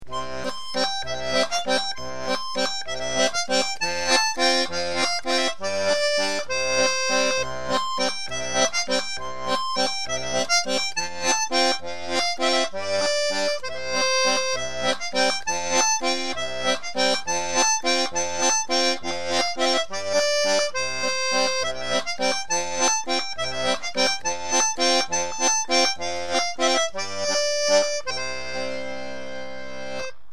Tablatures gratuites pour accordeon diatonique.
Valses --- Sommaire